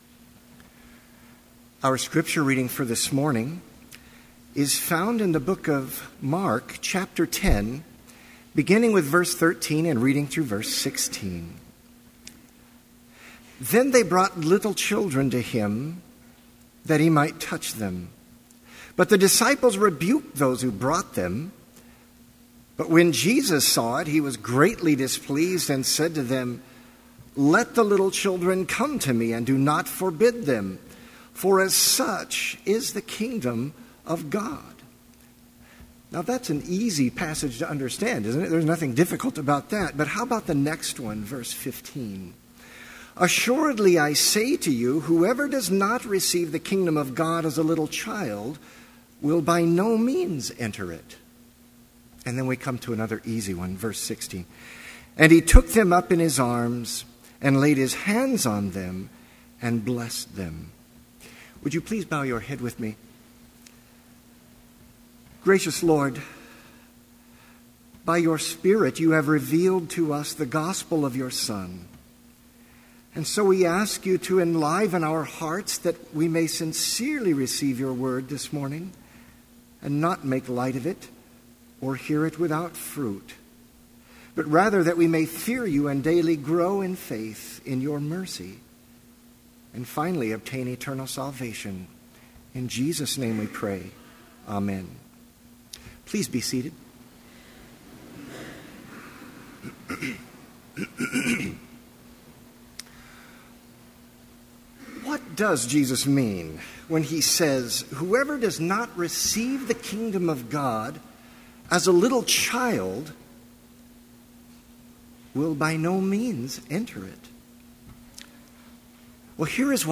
Complete service audio for Chapel - January 17, 2013